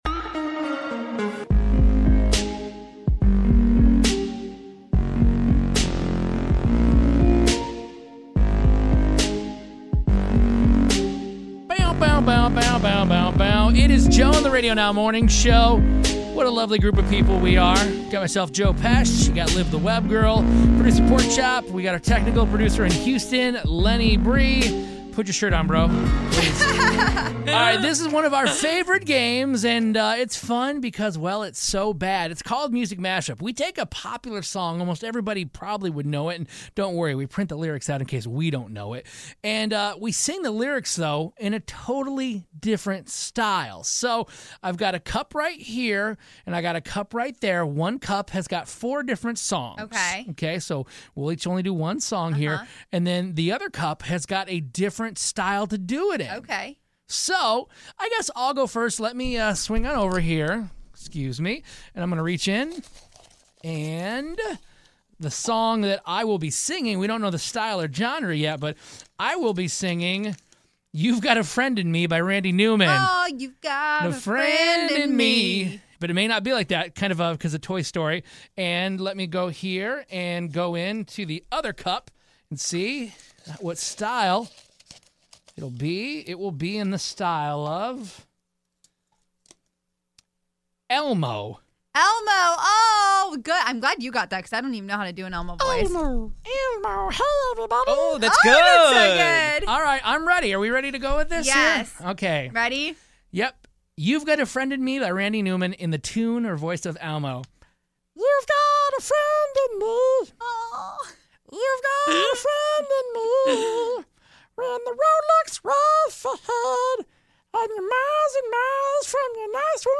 We play music mash-up and absolutely tried our best. You can decide who you think sounded the best.